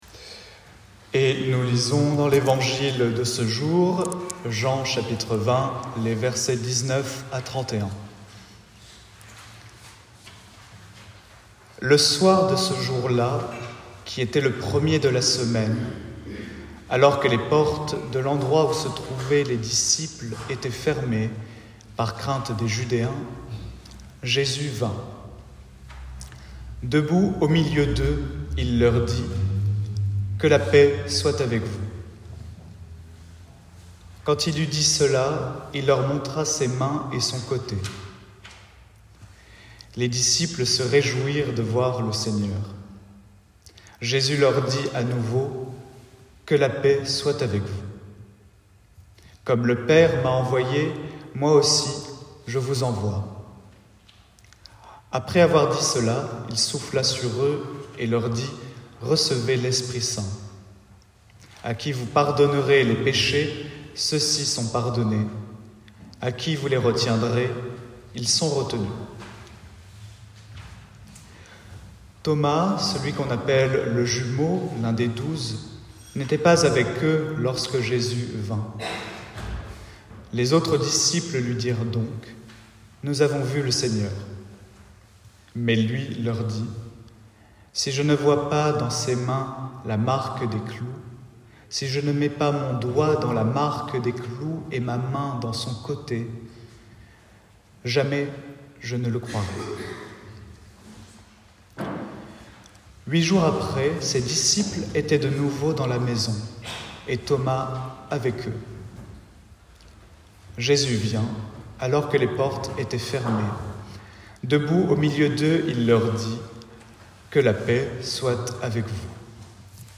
Prédication 07 04 24.pdf (50.18 Ko) Lecture Jean 20, 19-31, Prédication, piano.mp3 (52.39 Mo)